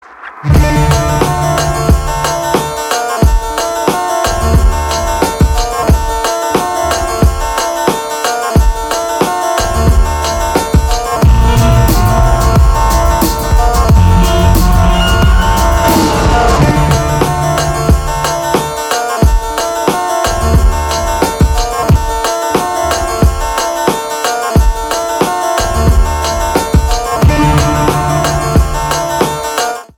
Крутая музыка инструментал без слов, подходит всем Ура!